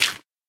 gravel3.ogg